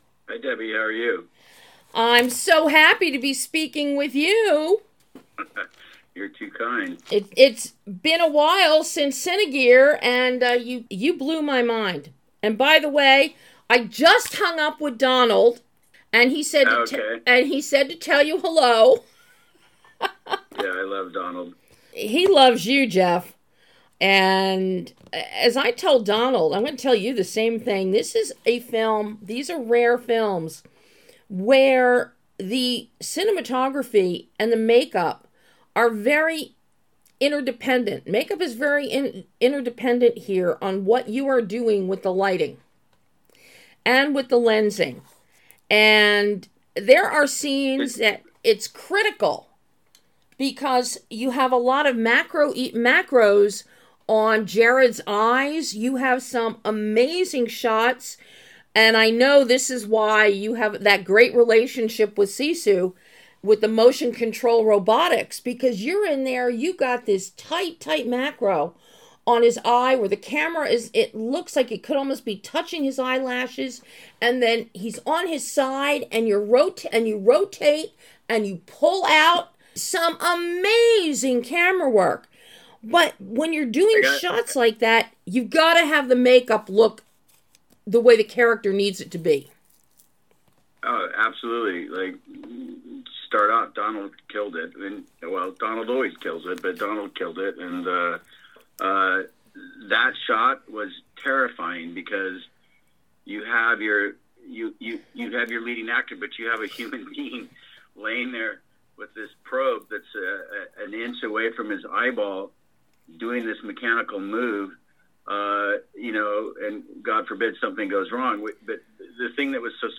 Cinematographer JEFF CRONENWETH delivers a hi-concept sci-fi universe with light, lens, and humanity in TRON: ARES - Exclusive Interview